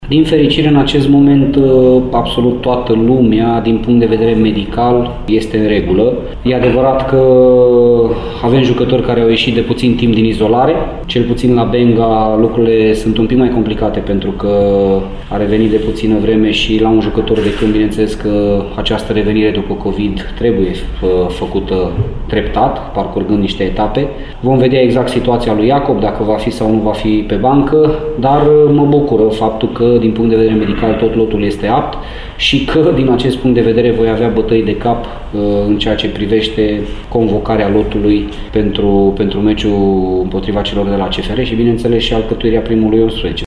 Antrenorul Laszlo Balint nu știe însă în ce măsură se va putea baza sâmbătă pe cei doi jucători vindecați de Covid-19: